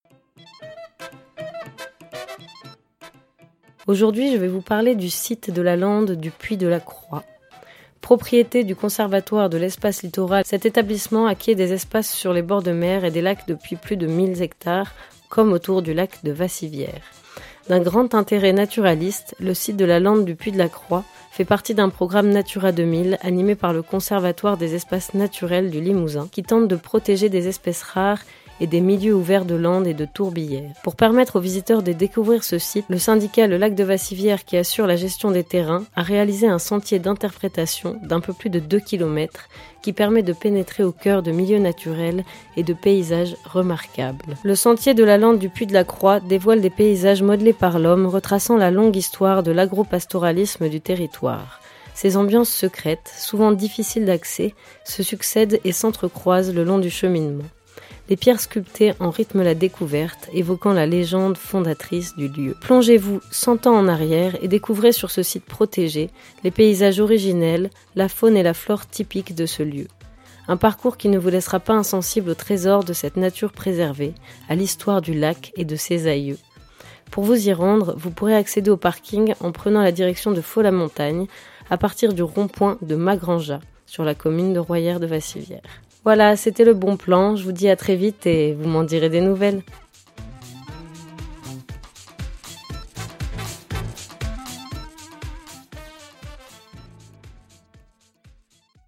Logo Radio Vassivière Dans le cadre de sa rubrique « Bons plans », Radio Vassivière vous emmène à la découverte de nos petits secrets d’ici…